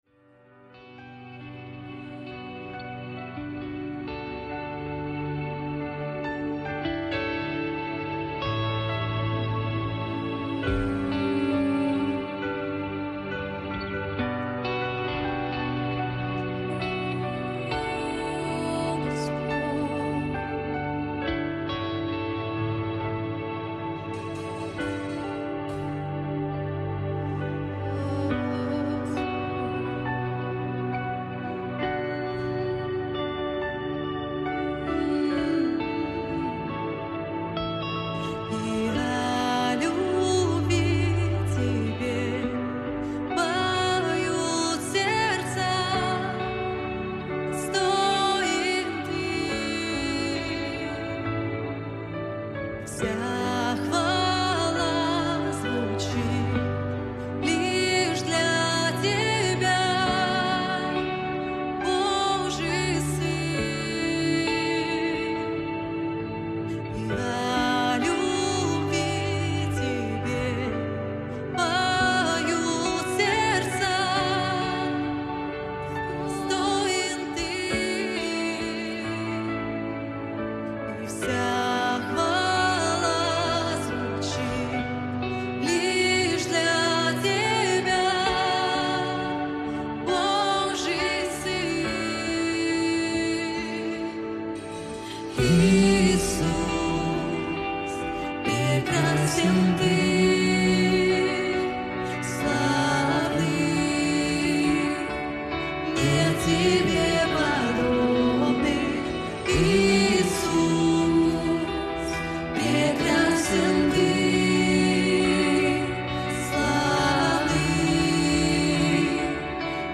90 просмотров 98 прослушиваний 6 скачиваний BPM: 140